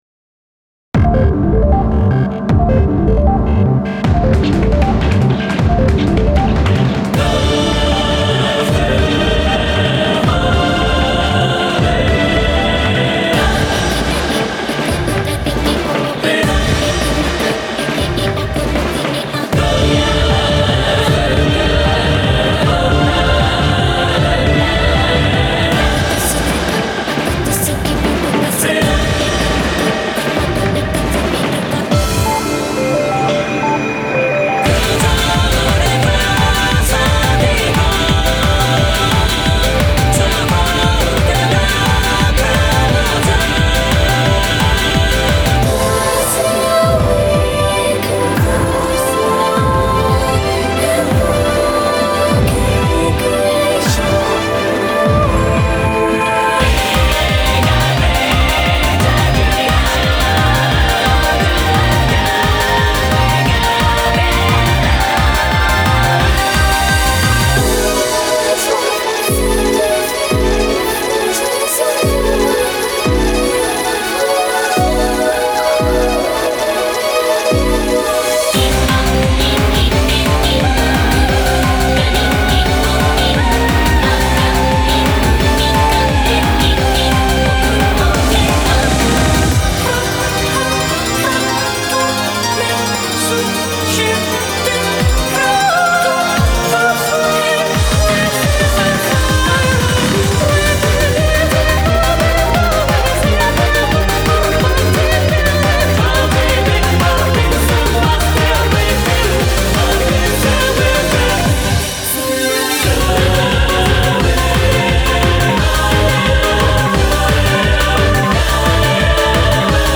BPM78-175